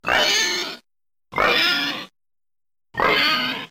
Index of /fastdownload/r_animals/files/pig
simogat.mp3